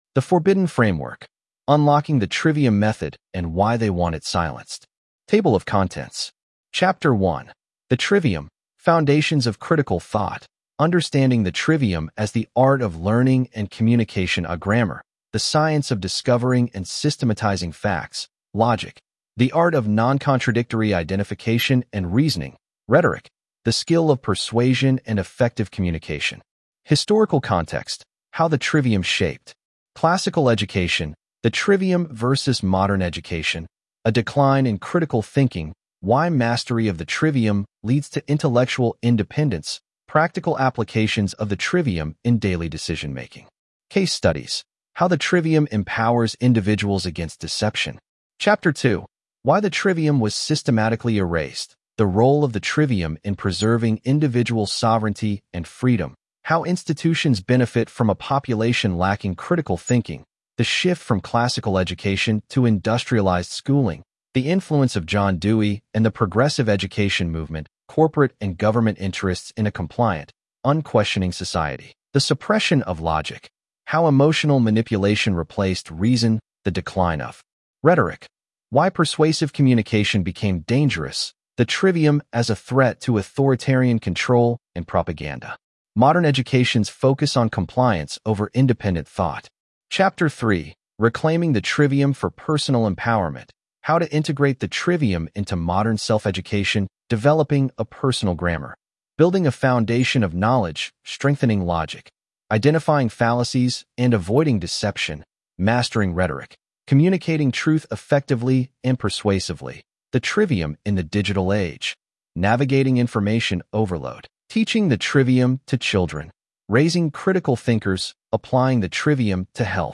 Trivium-Method-Audiobook.mp3